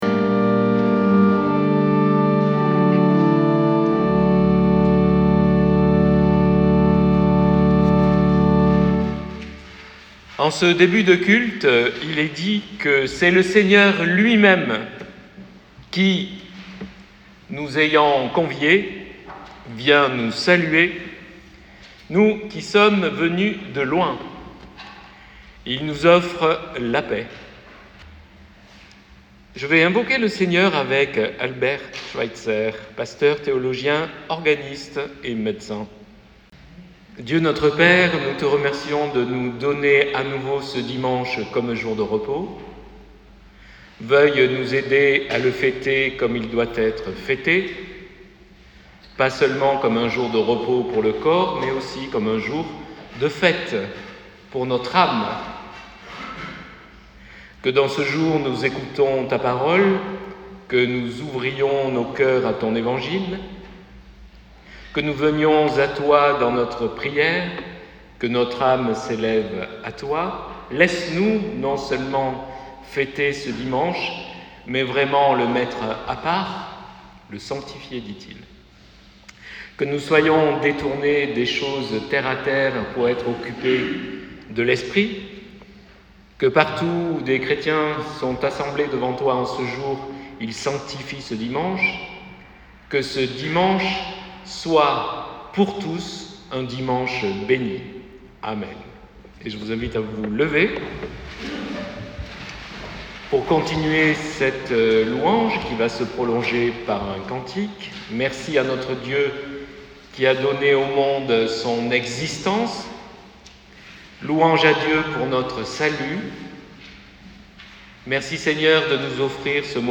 Prédication du 30 juin 2024.mp3 (73.2 Mo) Téléchargez la prédication du 30 juin 2024.pdf (580.23 Ko)